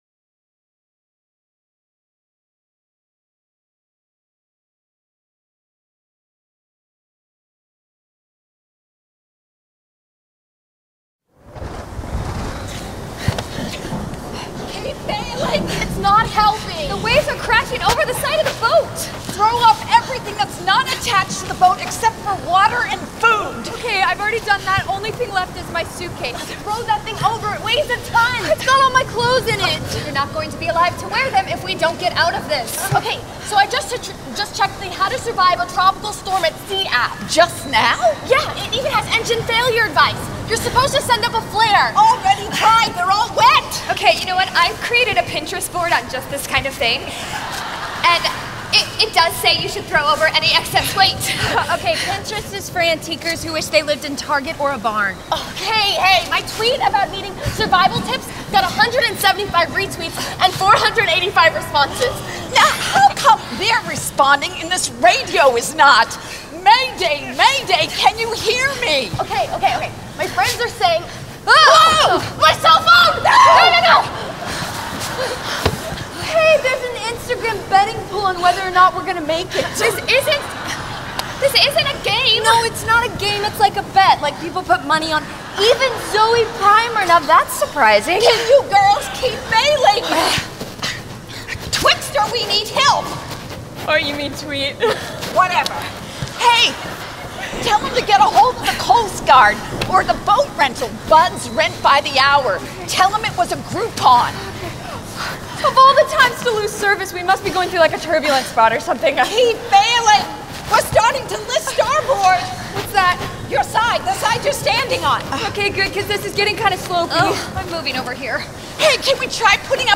Message 1 (Drama): The Storm